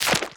LIQImpt_Goo Splatter_09.wav